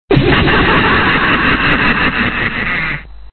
Efekty Dźwiękowe